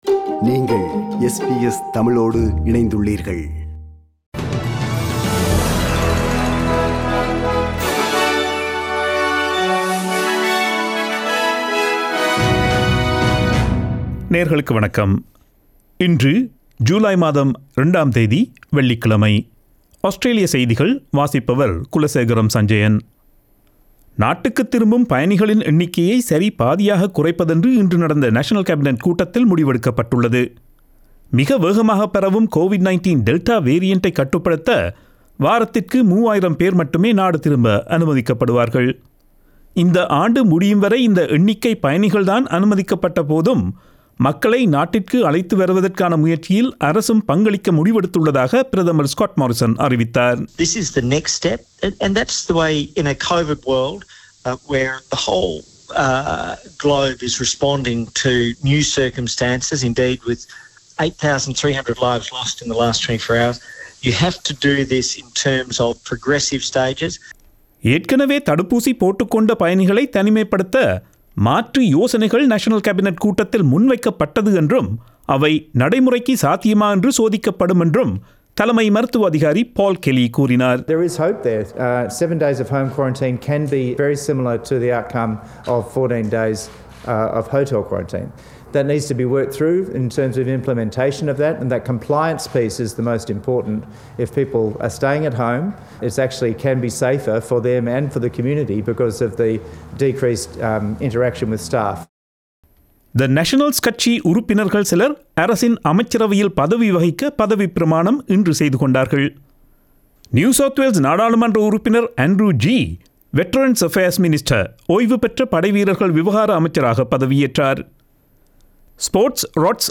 SBS தமிழ் ஒலிபரப்பின் இன்றைய (வெள்ளிக்கிழமை 02/07/2021) ஆஸ்திரேலியா குறித்த செய்திகள்.